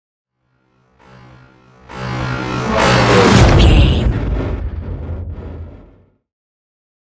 Sci fi vehicle whoosh and hit
Sound Effects
Atonal
futuristic
tension
woosh to hit